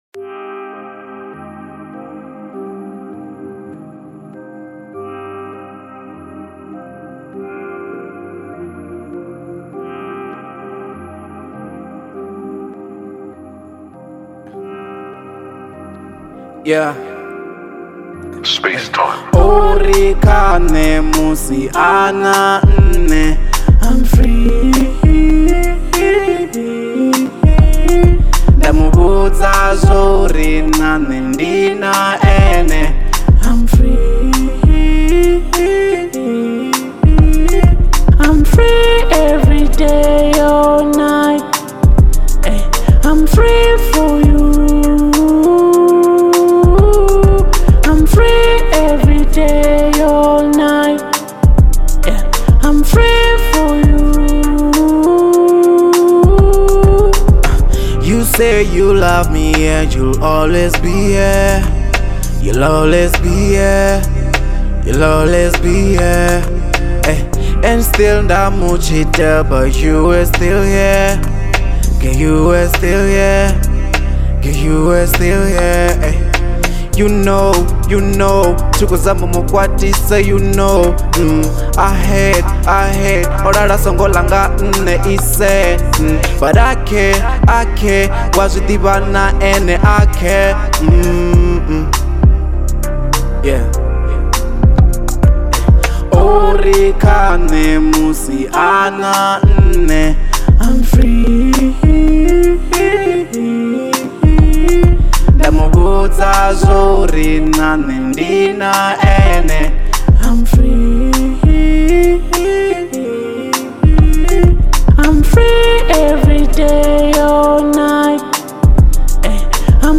02:38 Genre : Venrap Size